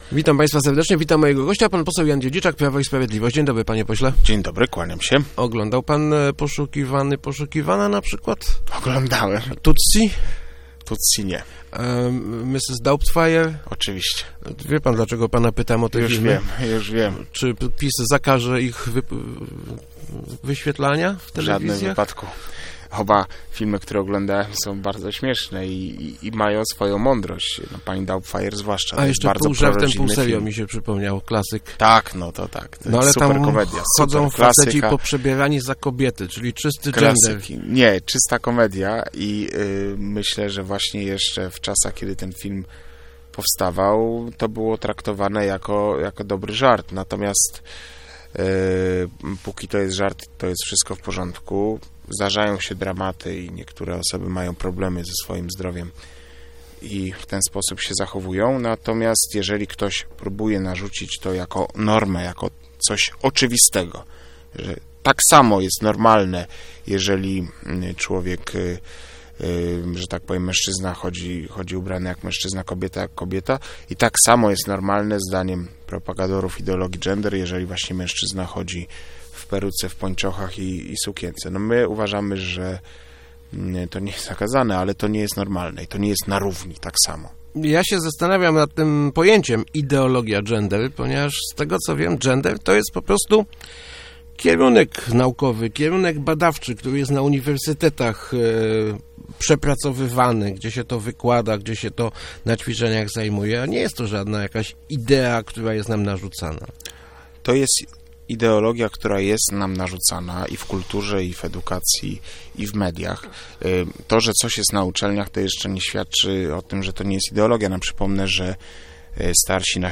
Niech każdy wychowuje dzieci jak chce, ale jesteśmy przeciwni narzucaniu tej ideologii innym - mówił w Rozmowach Elki poseł PiS Jan Dziedziczak, odnosząc się do projektu uchwały przeciwnej promocji gender w leszczyńskich placówkach edukacyjnych.